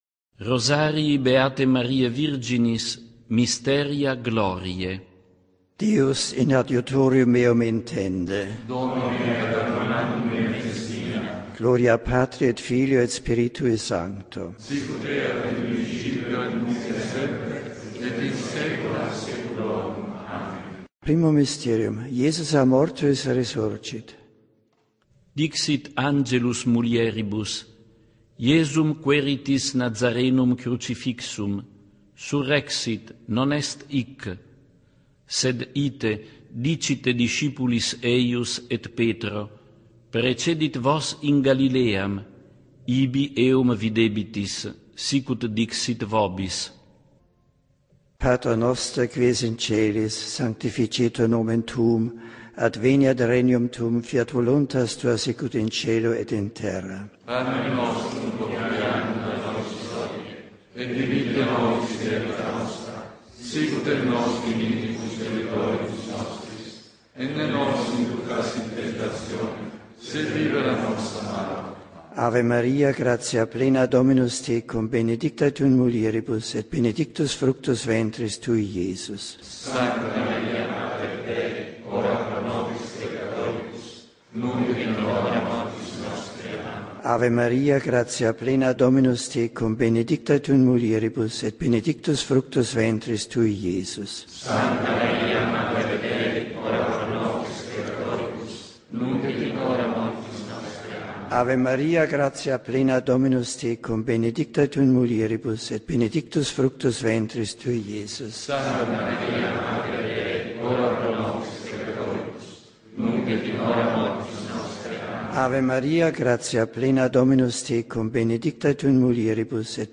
Misteria Gloriae (W–S) (audio) Trascription of the Glorious Mysteries Rosary in Latin as recited by Pope Emeritus Benedict XVI
Rosario-in-Latino-di-Benedetto-XVI-Misteri-Gloriosi.mp3